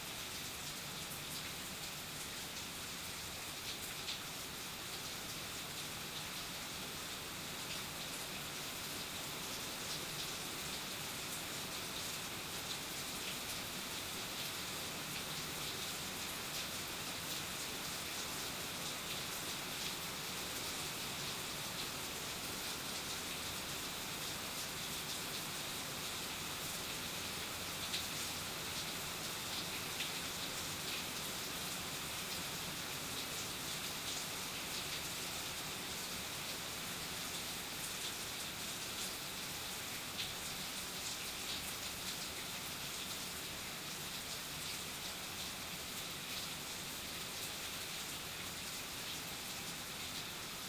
Weather Evening Suburbs Rainfall Concrete 02 BH2N_ambiX.wav